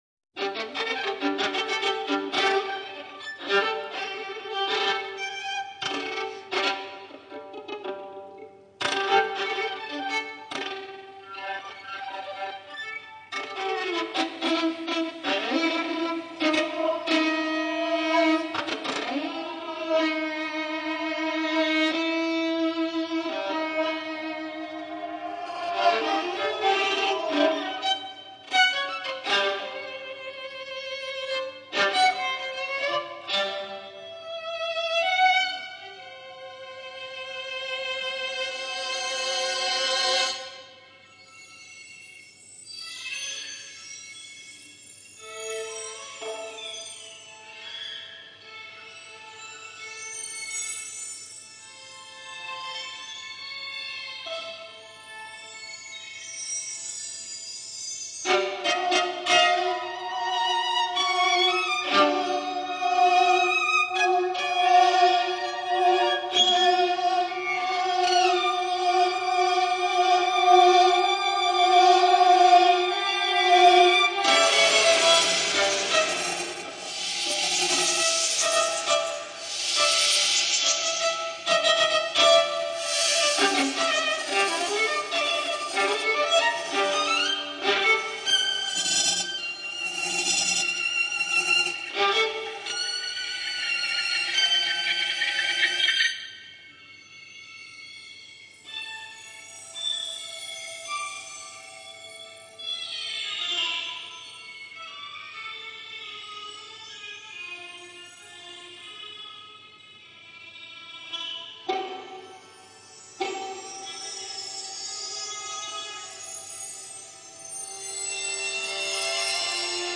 for violin, tape and live electronics (1995)
Violin